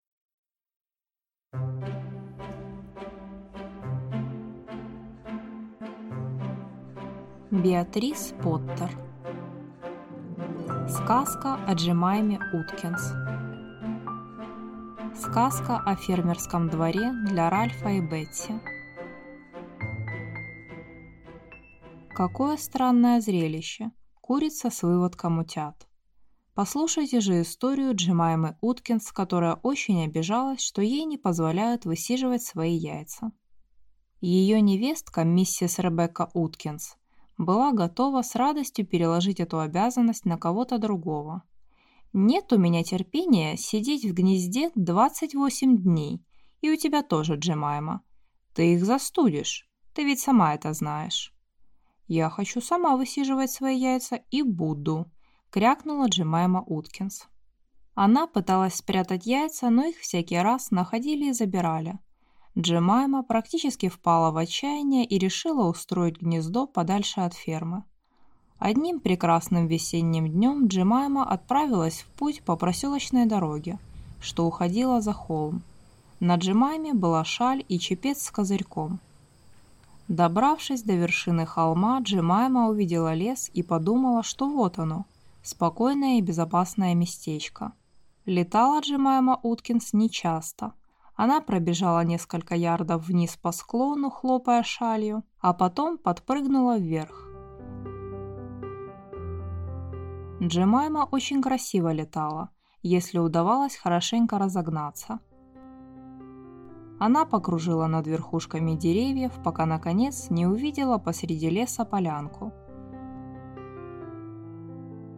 Аудиокнига Сказка о Джемайме Уткинс | Библиотека аудиокниг